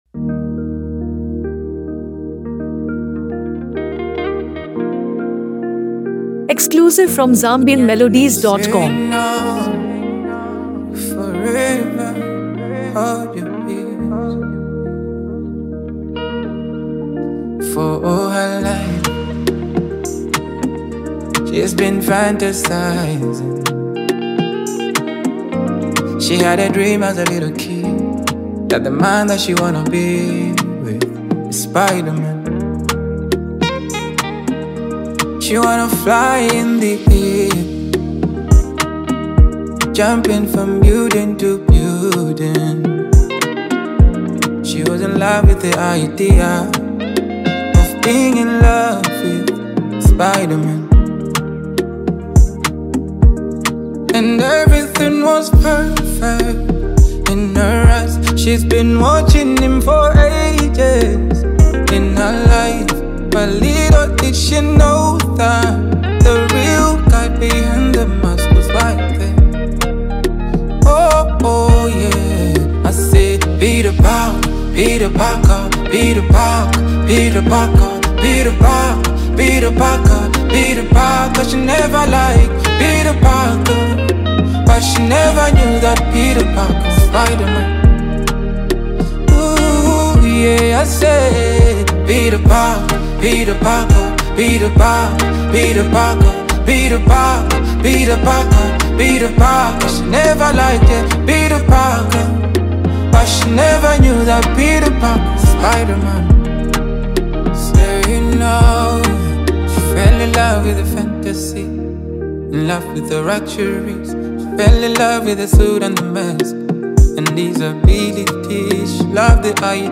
Afrobeat and R&B